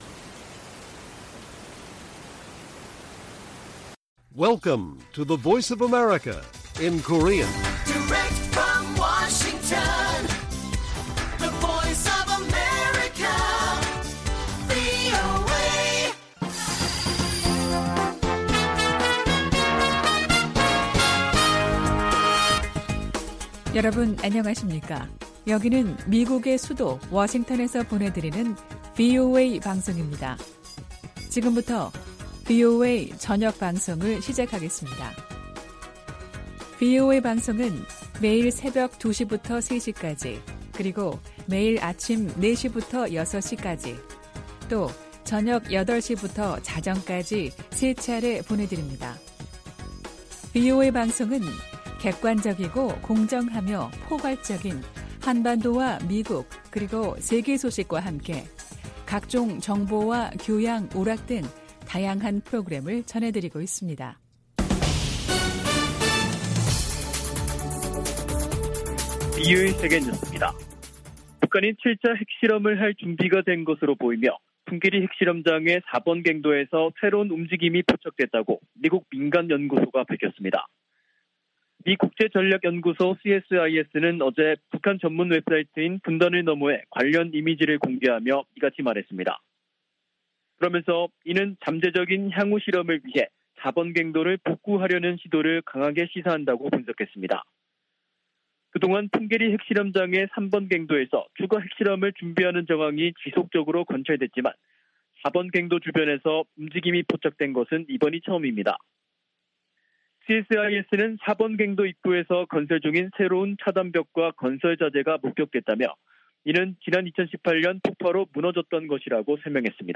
VOA 한국어 간판 뉴스 프로그램 '뉴스 투데이', 2022년 6월 16일 1부 방송입니다. 미 하원 세출위원회 국방 소위원회가 북한 관련 지출을 금지하는 내용을 담은 2023 회계연도 예산안을 승인했습니다. 북한 풍계리 핵실험장 4번 갱도에서 새로운 움직임이 포착됐다고 미국의 민간연구소가 밝혔습니다. 북한이 지난해 핵무기 개발에 6억4천200만 달러를 썼다는 추산이 나왔습니다.